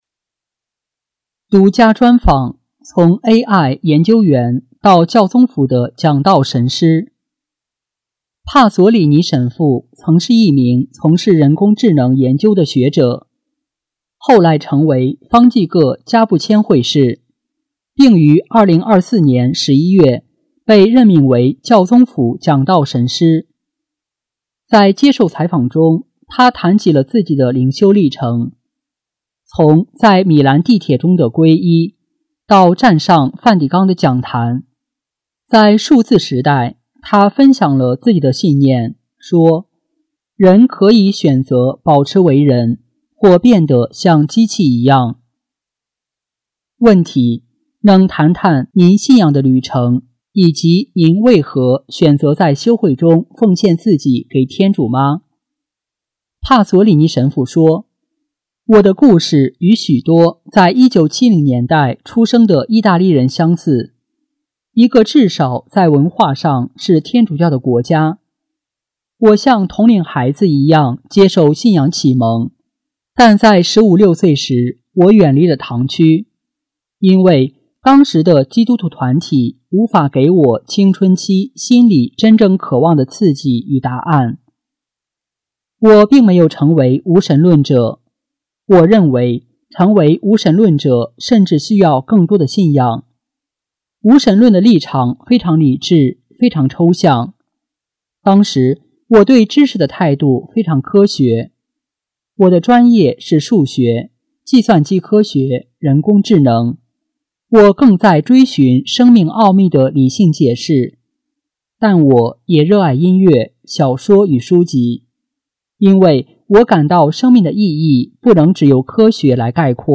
【公教新闻】| 独家专访：从AI研究员到教宗府的讲道神师